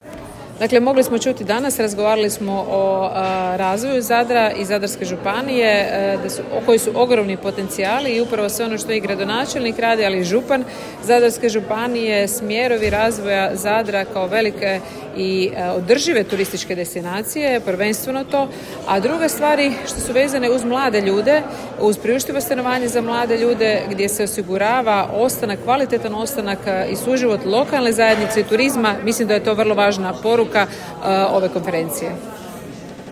Upravo je to pitanje otvorio prvi panel konferencije Evolution Next Level u Zadru, simbolično nazvan „Savršena temperatura mora?“, koji je okupio predstavnike europskih institucija, gospodarstva, investitora i regionalne politike.
Nikolina-Brnjac-panel.m4a